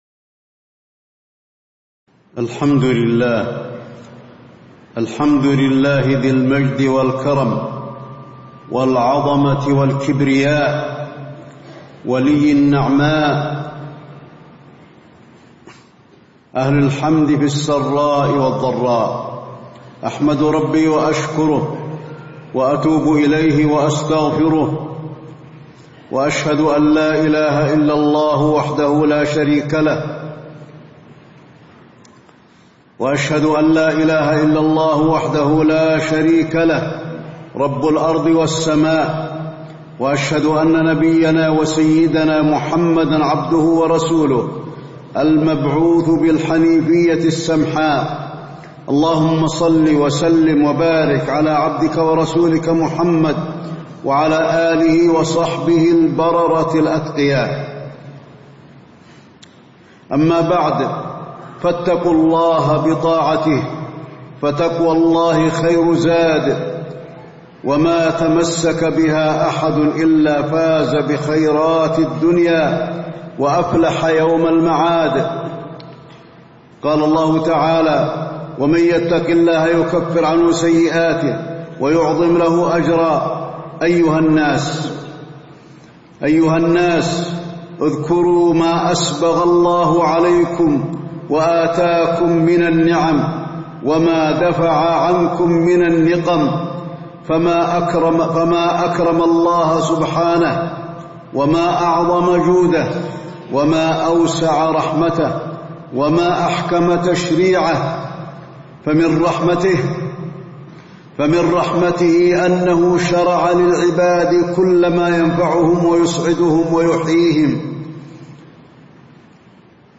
تاريخ النشر ١٧ محرم ١٤٣٧ هـ المكان: المسجد النبوي الشيخ: فضيلة الشيخ د. علي بن عبدالرحمن الحذيفي فضيلة الشيخ د. علي بن عبدالرحمن الحذيفي الأمن من أعظم النعم